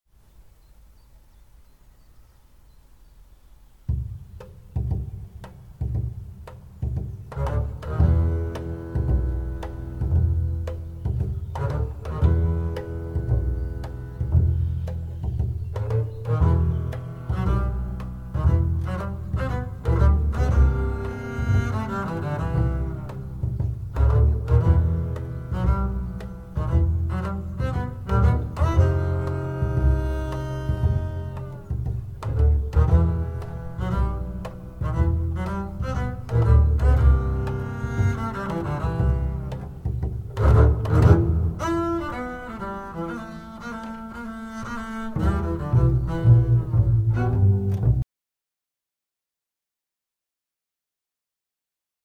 Contrabas
De grootste uit de strijkersfamilie en dus bespeel je mij staand.